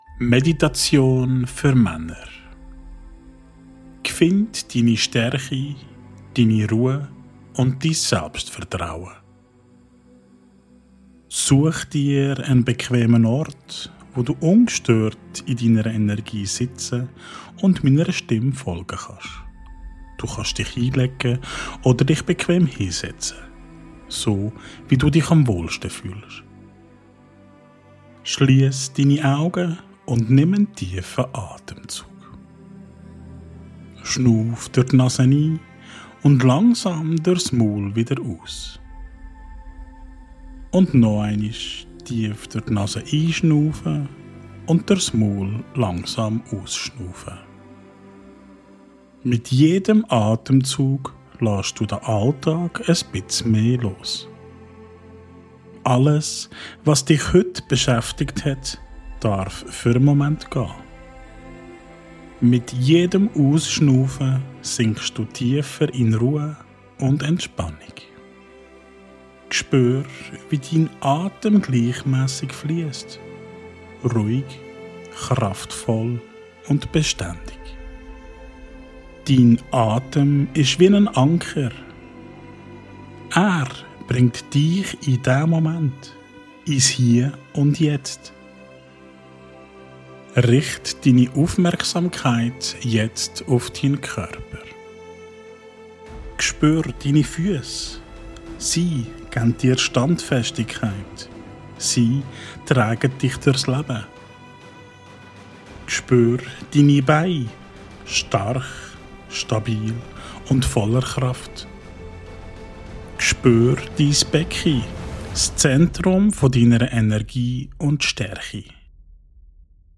Diese geführte Meditation ist für dich gemacht, wenn du wieder in deine Kraft kommen willst – als Mann, der klar, ruhig und selbstbewusst durchs Leben geht.